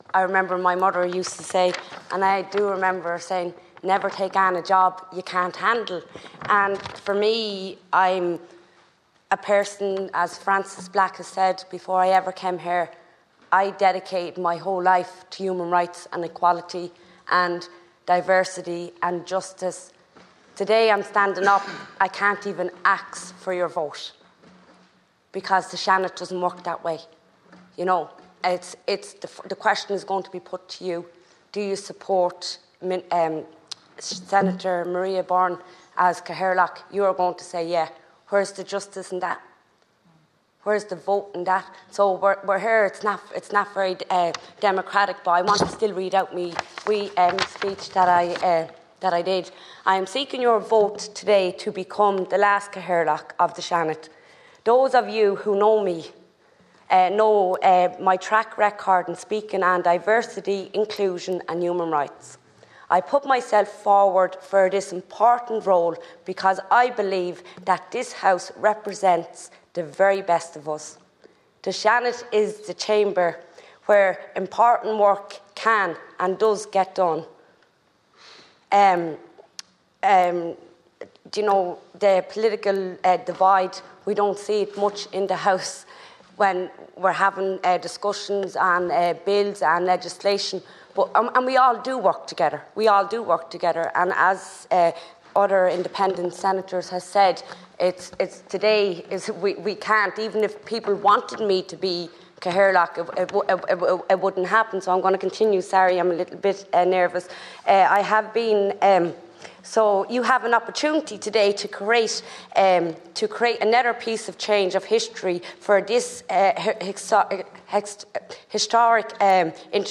Addressing the Seanad a short time ago, Senator Flynn labelled the process undemocratic: